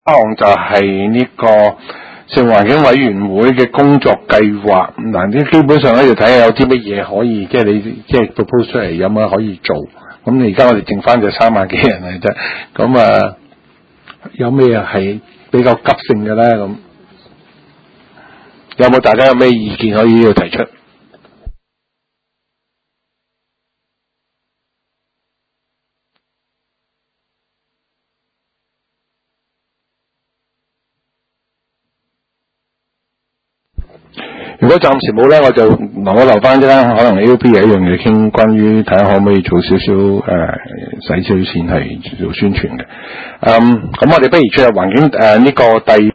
食物及環境衛生委員會第十二次會議
灣仔民政事務處區議會會議室